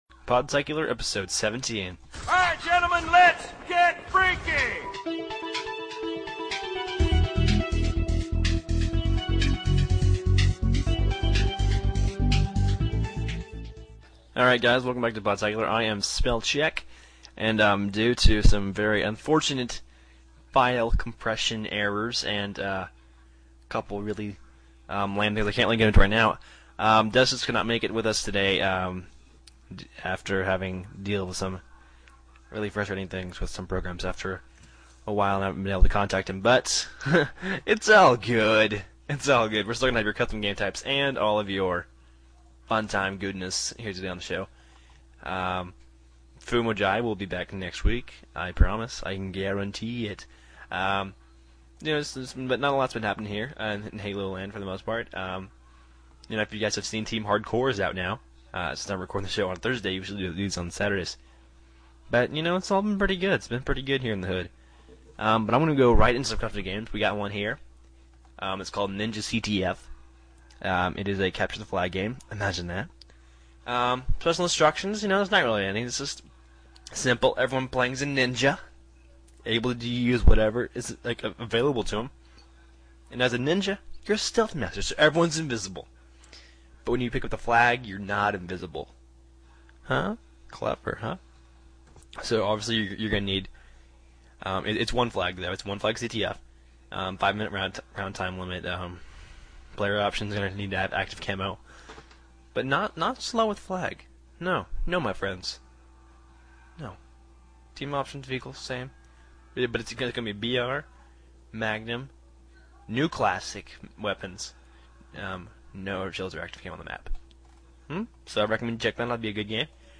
So I recorded a NEW show, by myself (but with your segments/voicemails) and it was a decent episode.